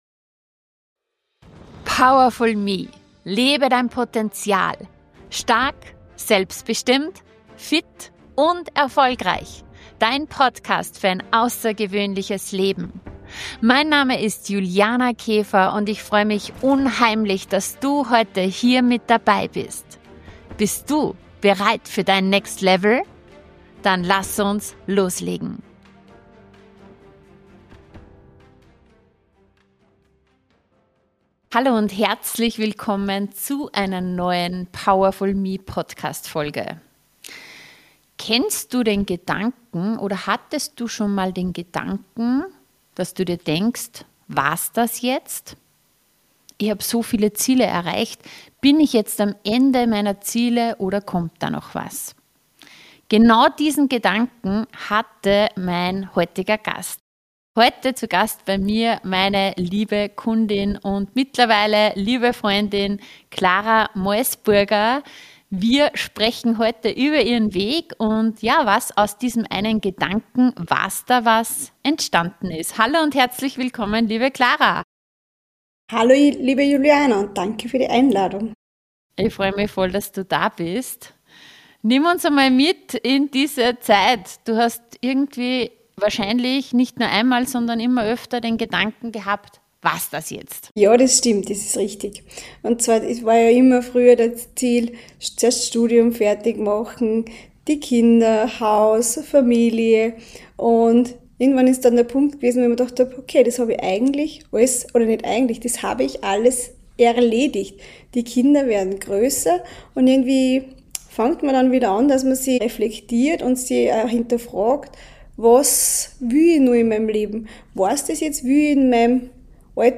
Du erfährst: - Warum Sicherheit oft Stillstand bedeutet - Wie du wieder in deine Energie und Selbstwirksamkeit kommst - Was passiert, wenn du dir erlaubst, mehr zu wollen Ein ehrliches Gespräch über Mut, Veränderung und den Moment, in dem du beginnst, dein Leben aktiv zu gestalten.